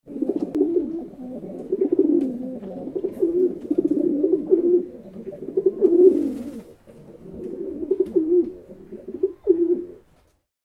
جلوه های صوتی
دانلود صدای کبوتر برای کودکان از ساعد نیوز با لینک مستقیم و کیفیت بالا